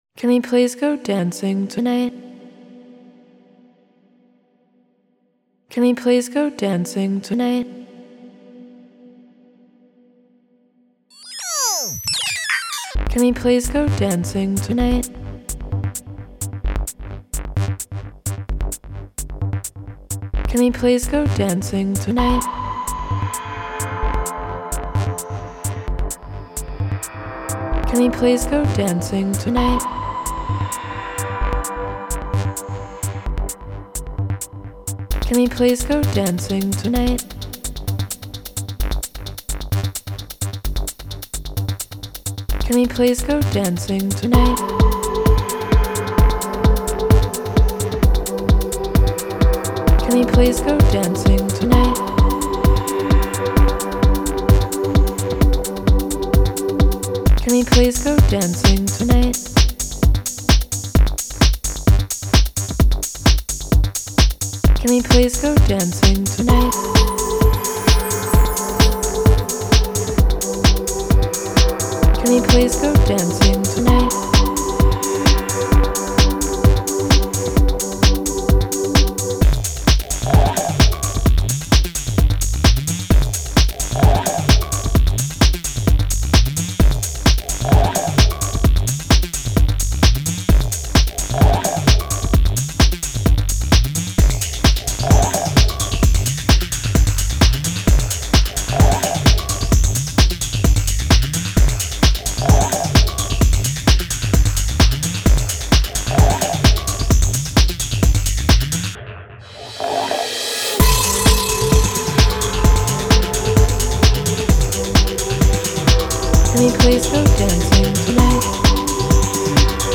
Genre Techno